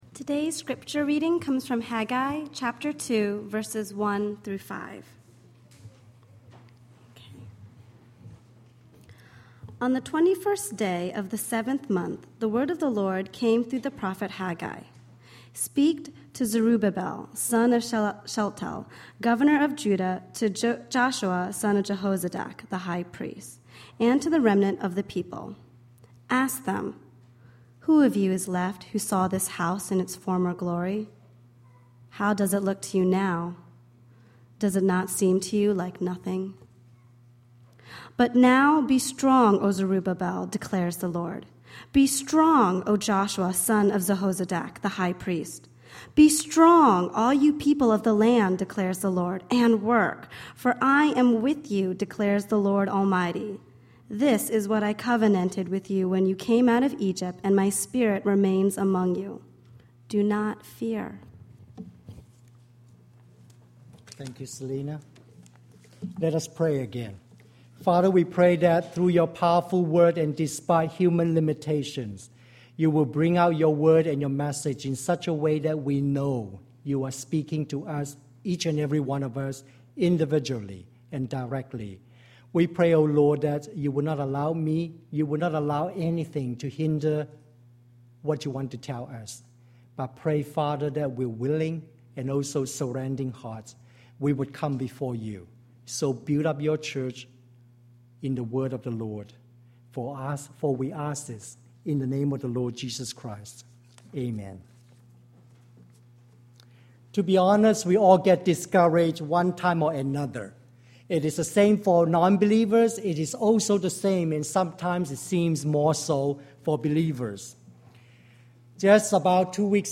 Overcoming Disappointment: May 24, 2009 Sermon